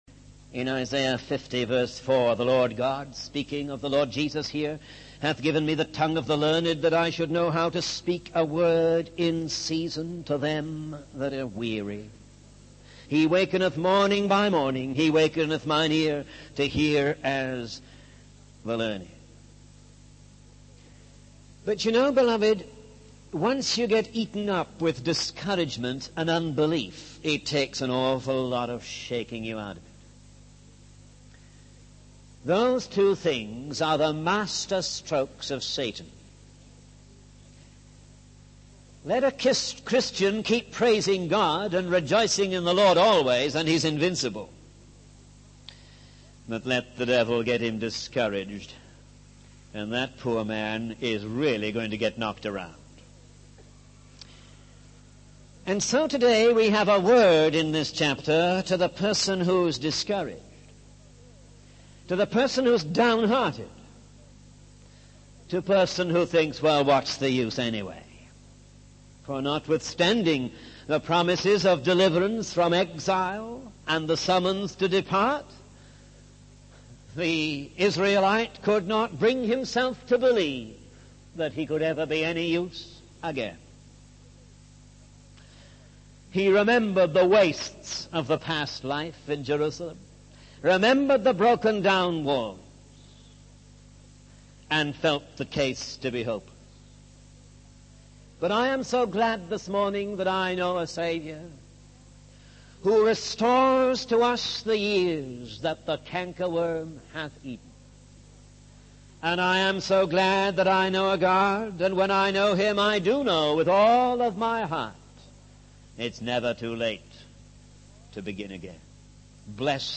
In this sermon, the preacher addresses those who are feeling discouraged and downhearted.